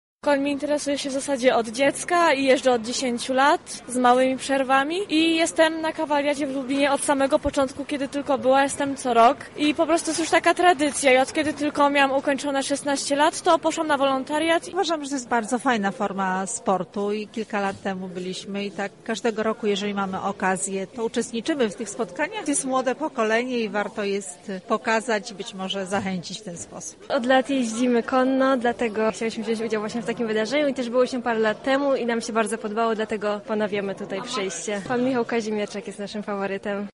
Zapytaliśmy widzów o wrażenia: